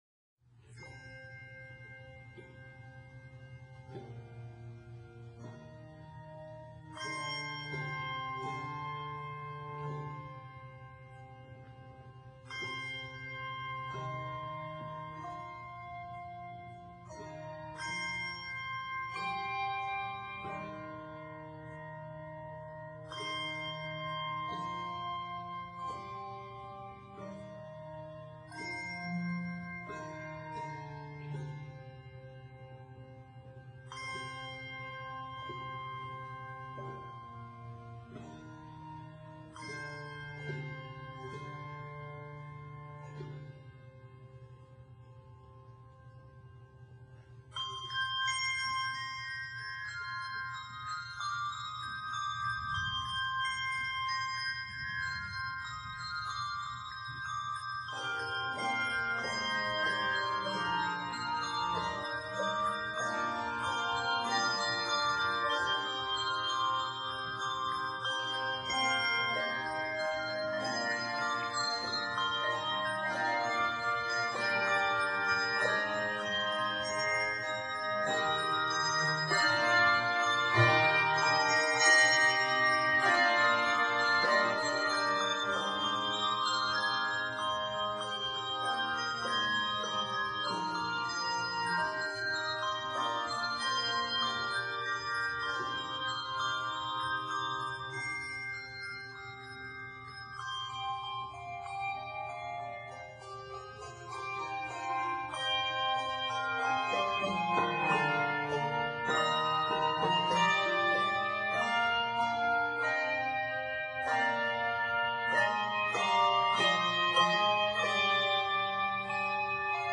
The finale section is boldly majestic and truly powerful.